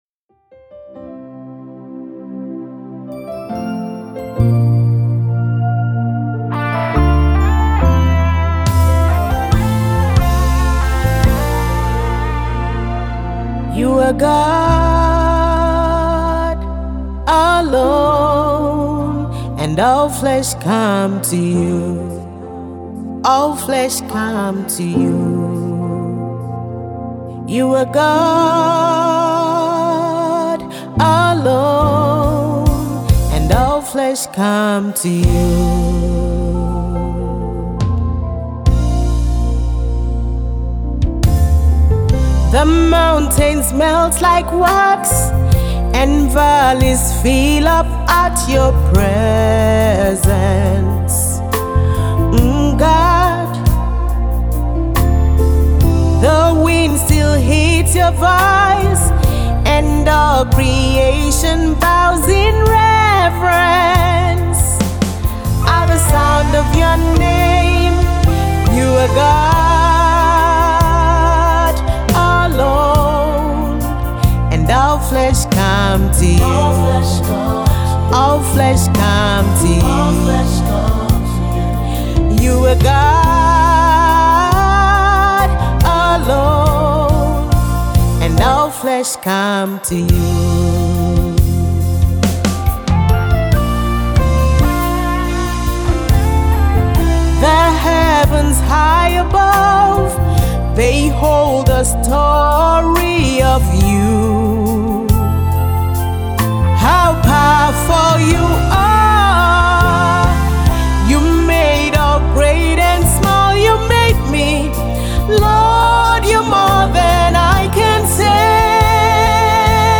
a cool tempo and meditational song